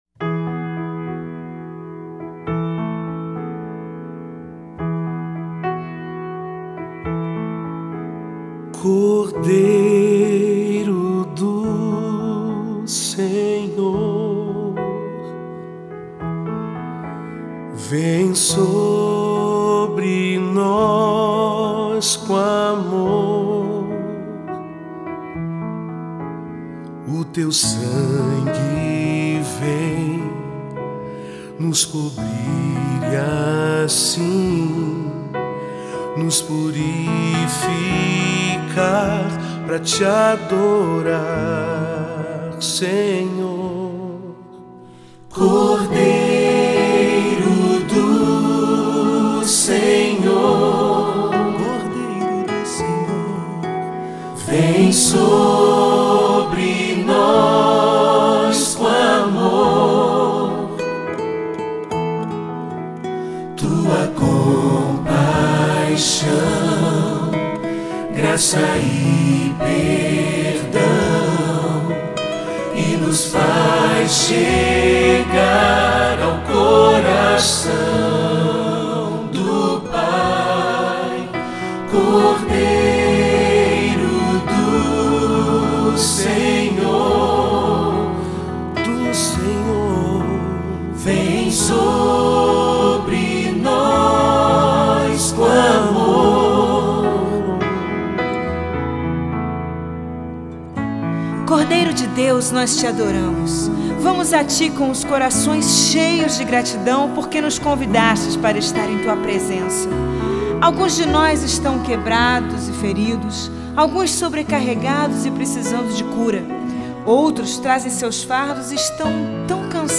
Os arranjos são fáceis e gostosos de cantar!